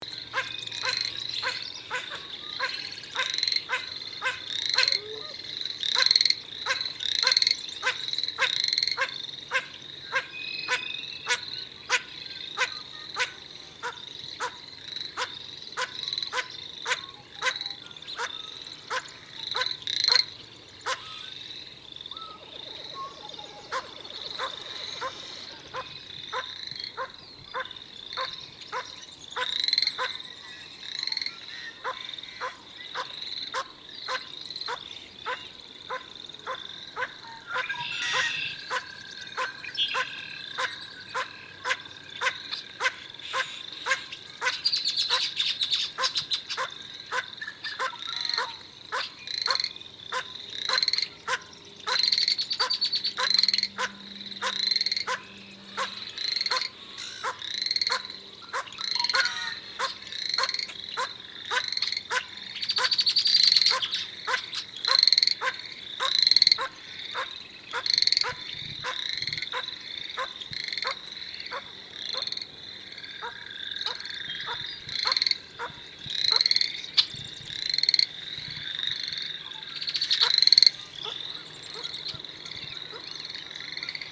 Audio Call